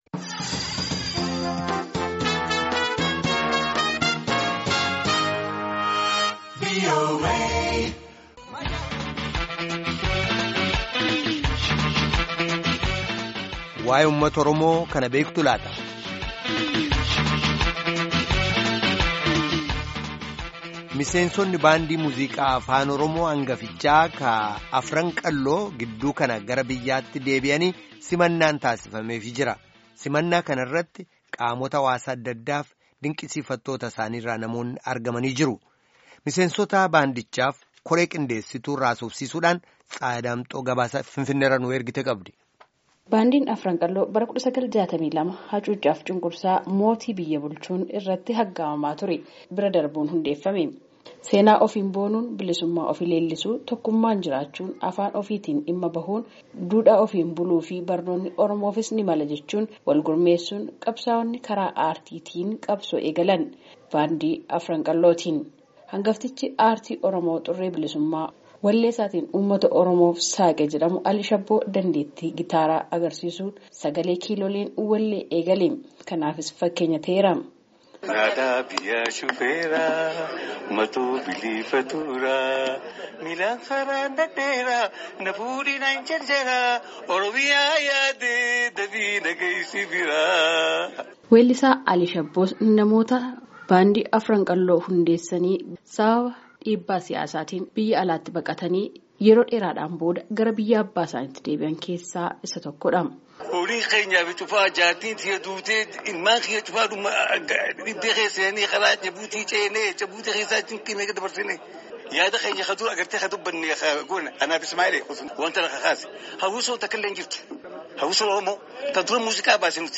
FINFINNEE —